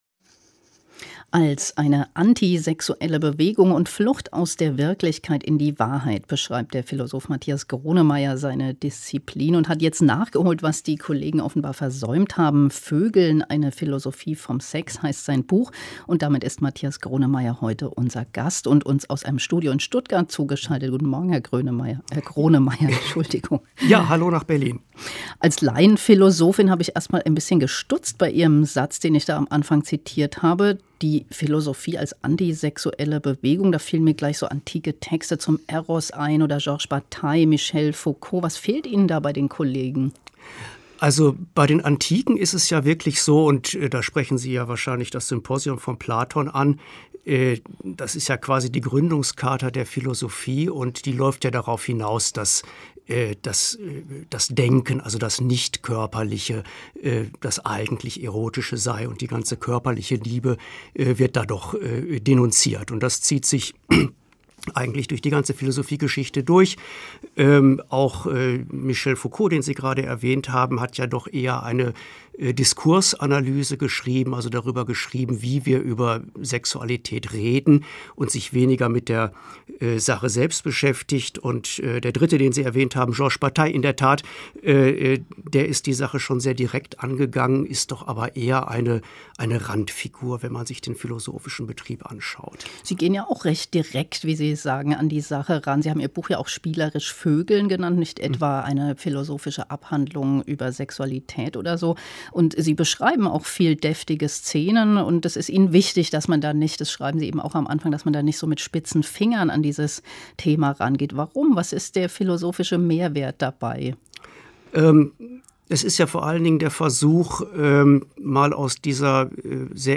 Auszug aus dem Interview am 12.10.2016: